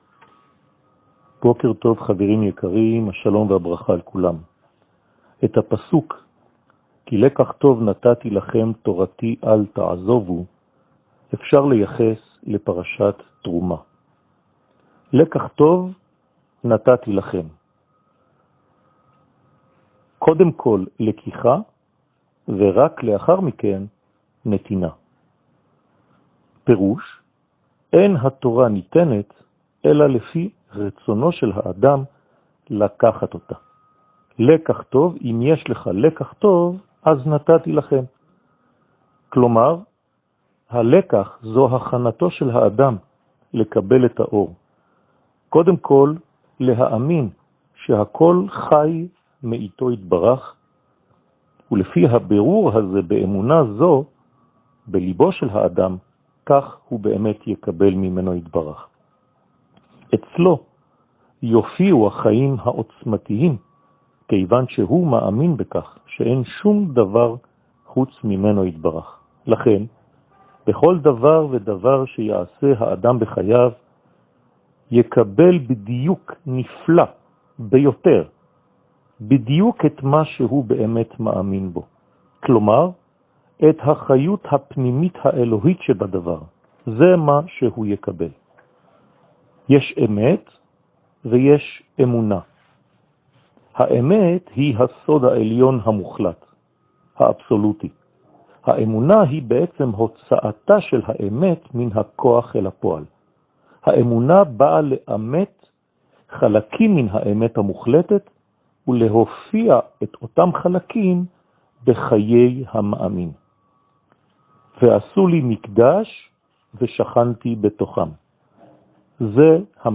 שיעור מ 15 פברואר 2021
שיעורים קצרים